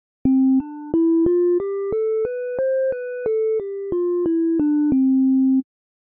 contrast with just major diatonic scale
JI_diatonic_scale.ogg.mp3